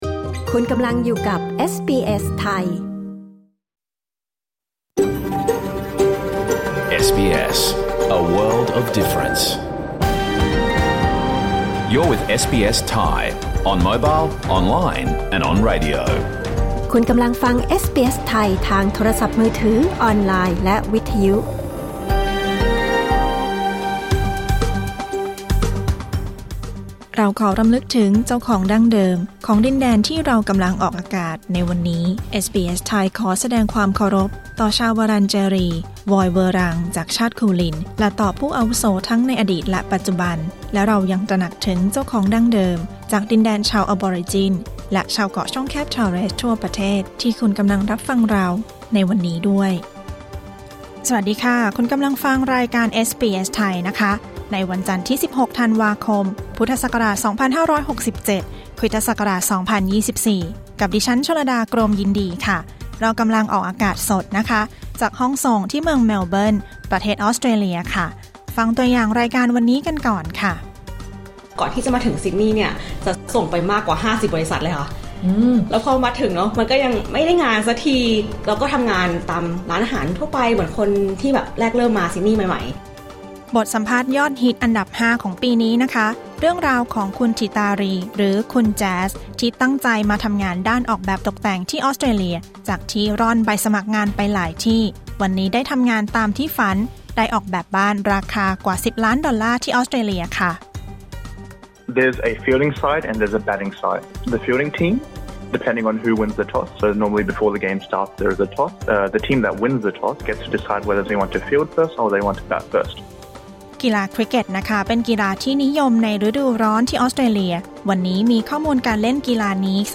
รายการสด 16 ธันวาคม 2567